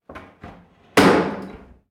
Abrir el cerrojo de una puerta de entrada a una vivienda